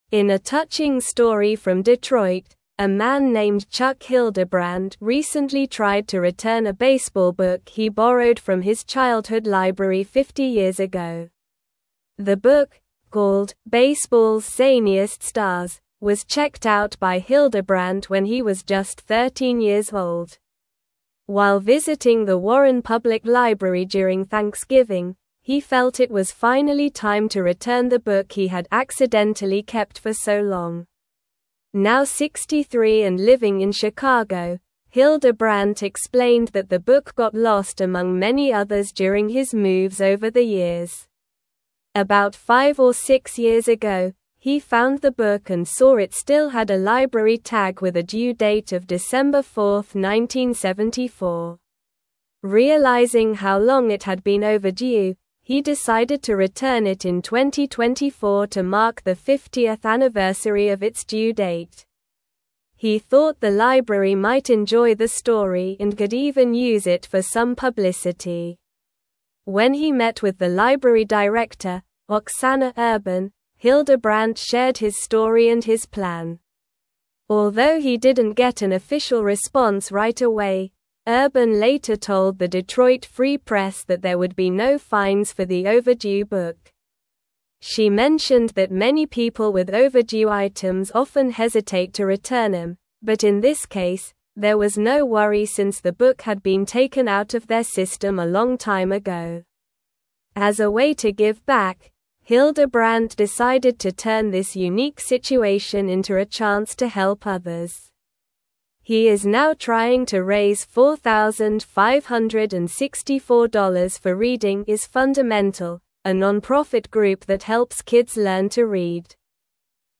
Slow
English-Newsroom-Upper-Intermediate-SLOW-Reading-Man-Returns-Overdue-Library-Book-After-50-Years.mp3